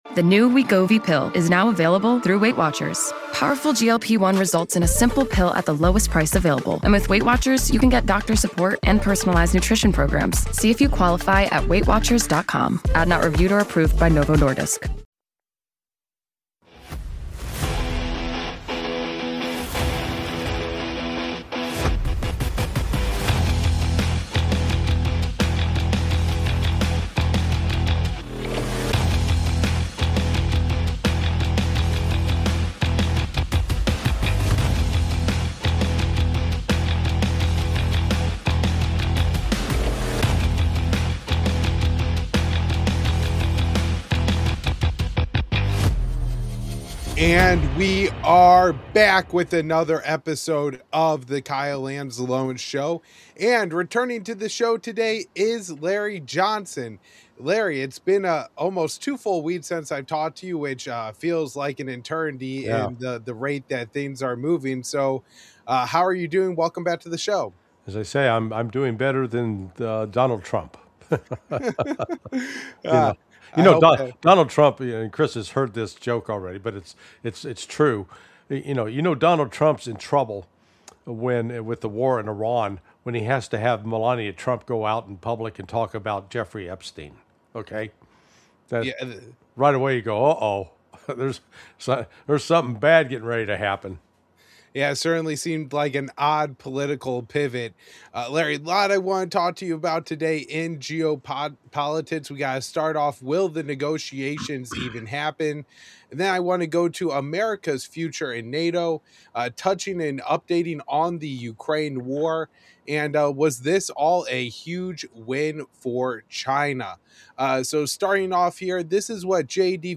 If you’re searching for clear geopolitical analysis on Iran negotiations, the Strait of Hormuz, US foreign policy, NATO cohesion, and the future of Ukraine, this conversation connects the dots.